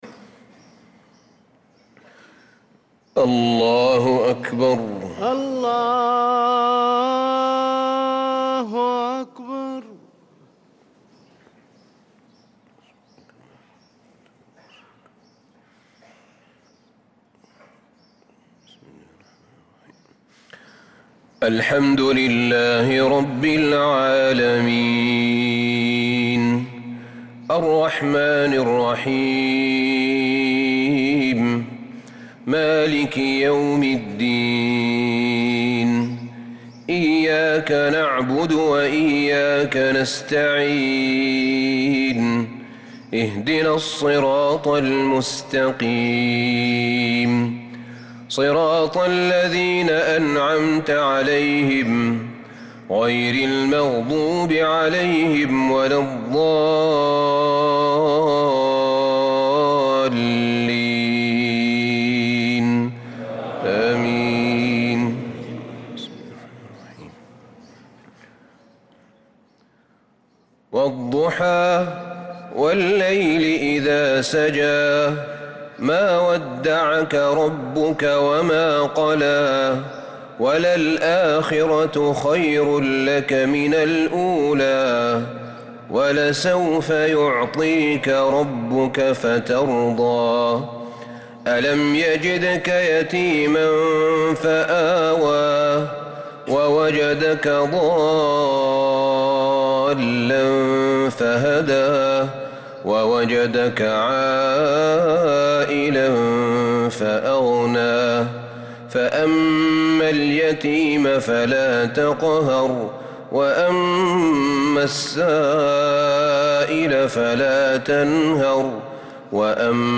صلاة المغرب للقارئ أحمد بن طالب حميد 20 رجب 1446 هـ
تِلَاوَات الْحَرَمَيْن .